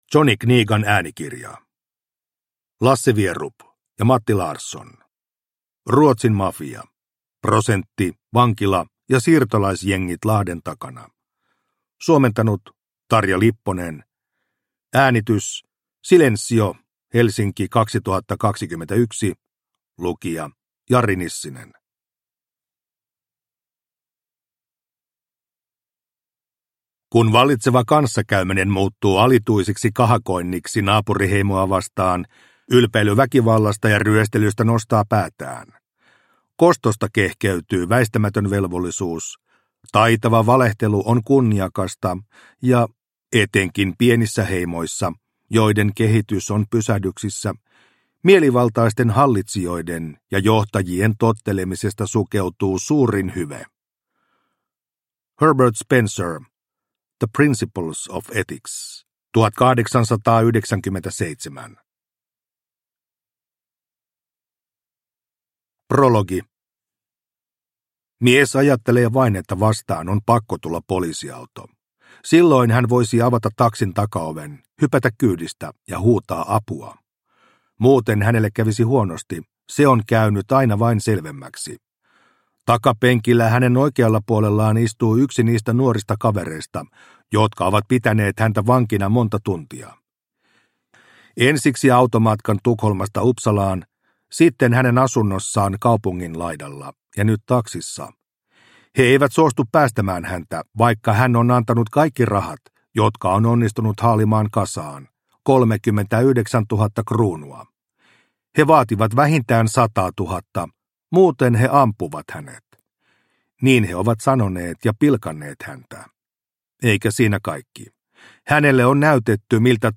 Ruotsin mafia – Ljudbok – Laddas ner